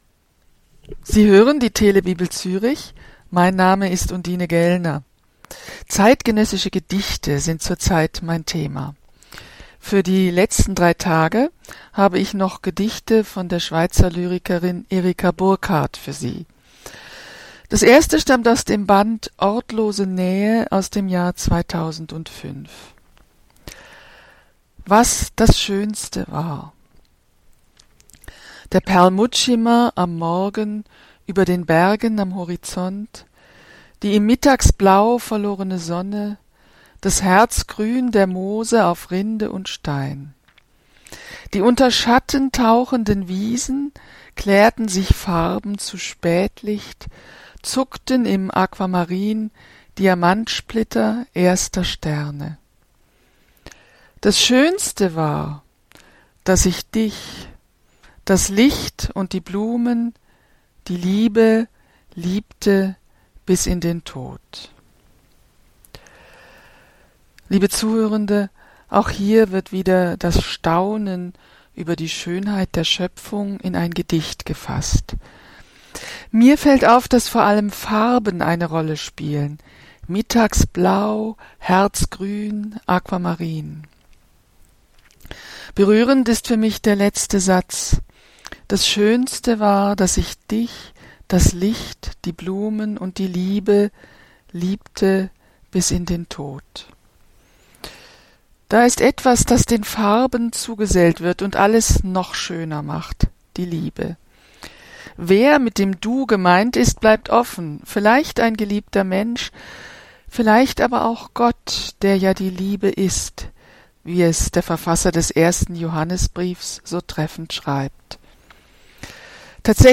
Gedicht Erika Burkhart, Was das Schönste war